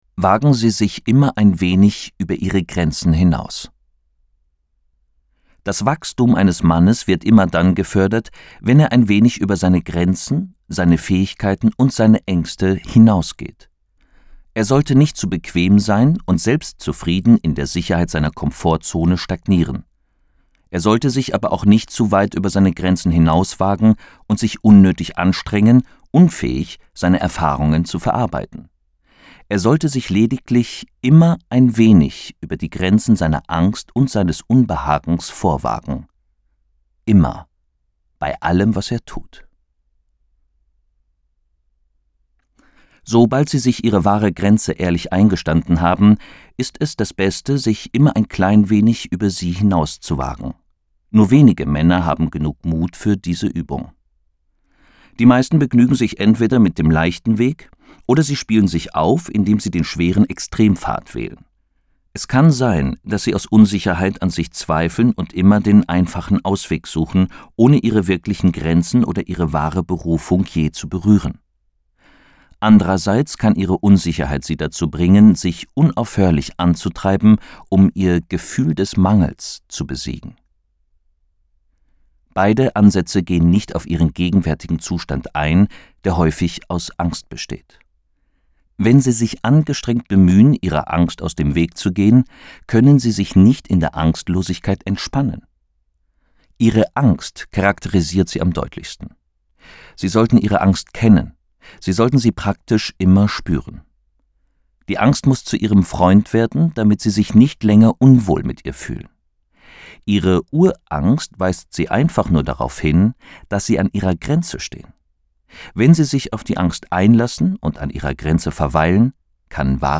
Der Weg des wahren Mannes Ein Leitfaden für Meisterschaft in Beziehungen, Beruf und Sexualität. Autorisierte Hörfassung David Deida